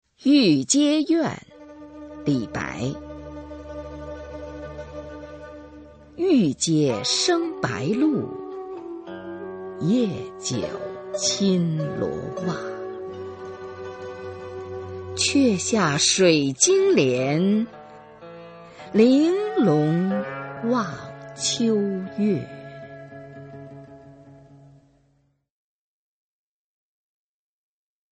[隋唐诗词诵读]李白-玉阶怨 古诗文诵读